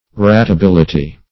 Ratability \Rat`a*bil"i*ty\ (r[=a]t`[.a]*b[i^]l"[i^]*t[y^]), n.